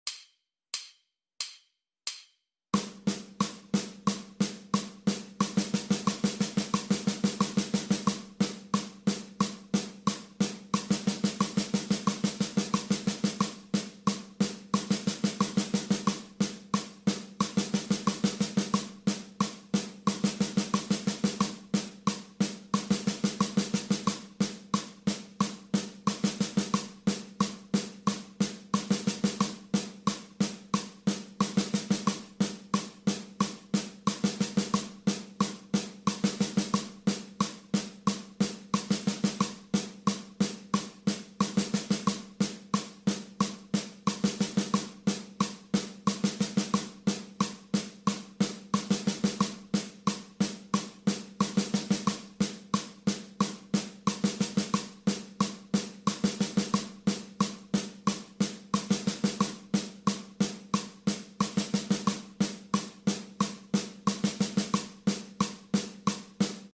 Snare drum warm up — sound effects free download
Mp3 Sound Effect Snare drum warm up — 16th notes. In this exercise, suitable for beginners, we focus specifically on leading with the right hand.